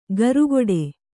♪ garugoḍe